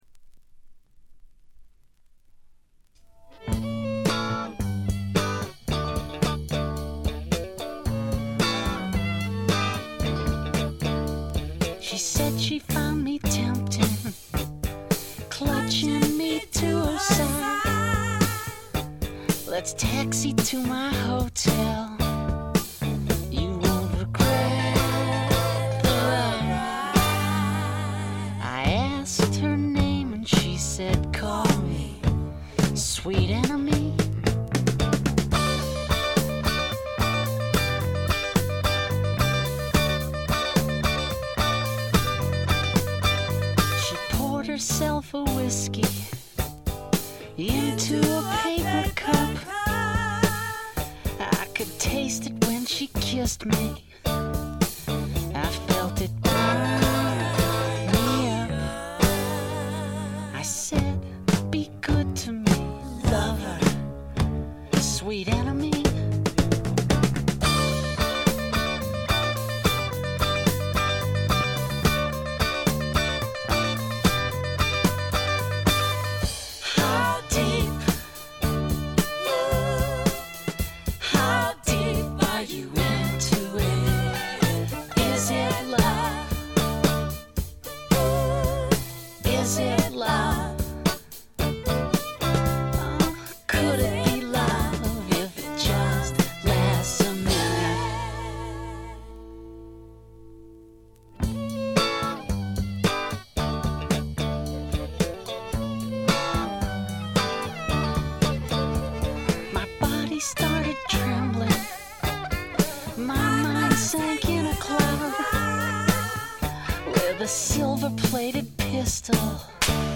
気付いたのはこれぐらい、ほとんどノイズ感無し。
試聴曲は現品からの取り込み音源です。